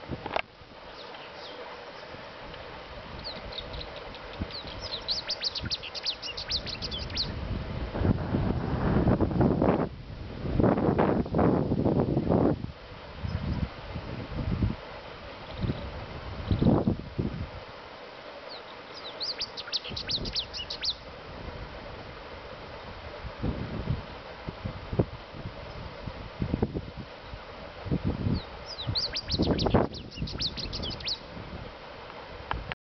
Chestnut-throated Seedeater (Sporophila telasco)
Sex: Male
Life Stage: Adult
Country: Peru
Detailed location: Caral
Condition: Wild
Certainty: Photographed, Recorded vocal